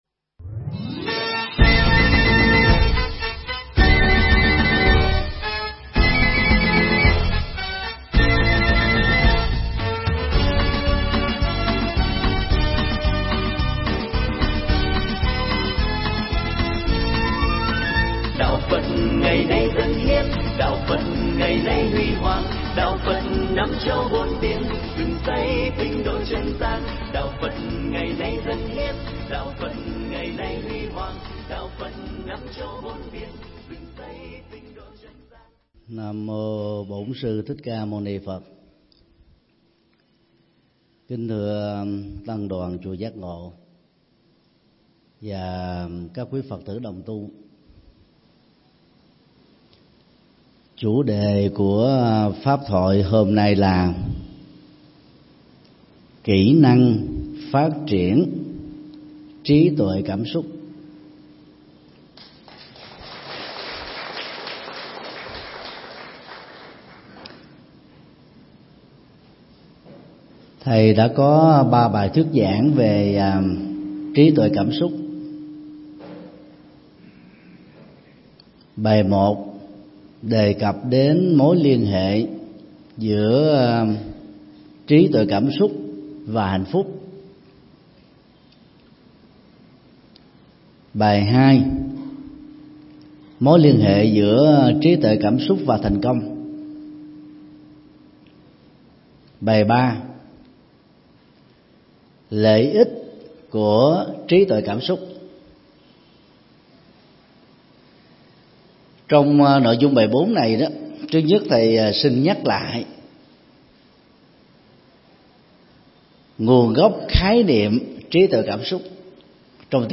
Mp3 Thuyết Giảng Kỹ Năng Phát Triển Trí Tuệ Cảm Xúc – Thượng Tọa Thích Nhật Từ giảng tại chùa Giác Ngộ, ngày 12 tháng 7 năm 2018